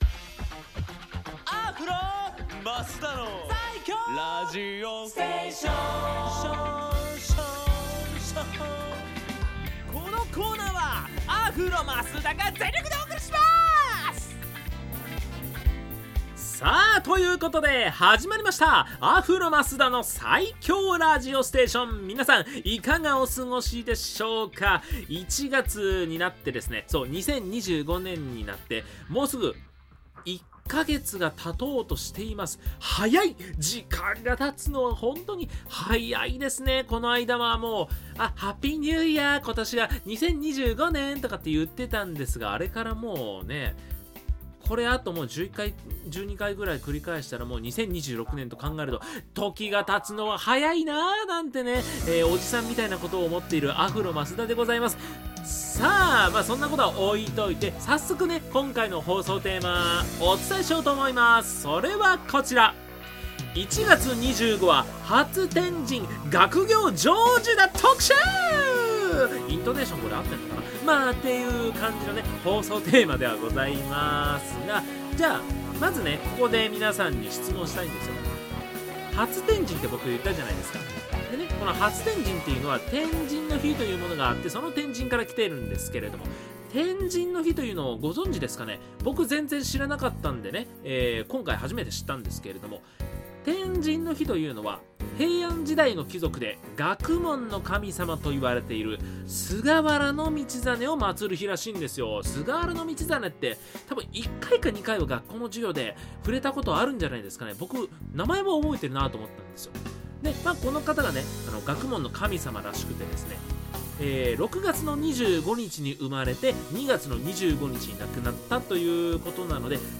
こちらが放送音源です♪